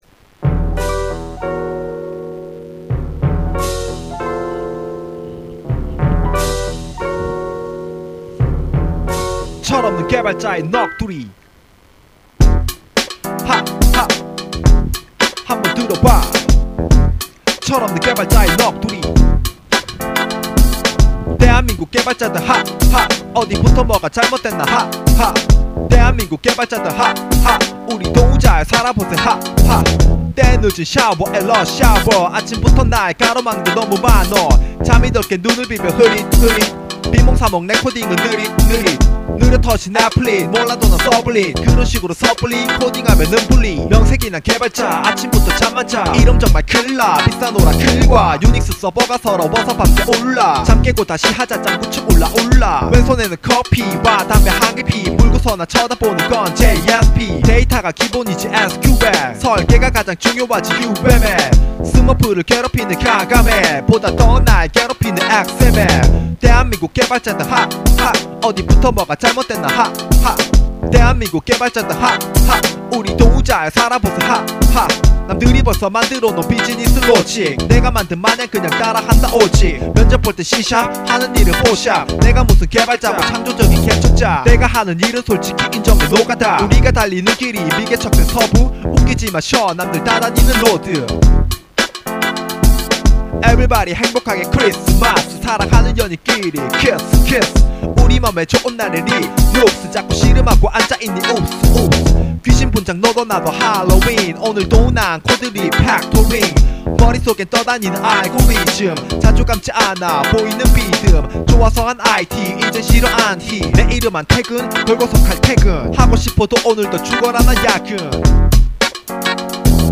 이친구 재미있게 끝에 운율을 맞추어서 만들었군요.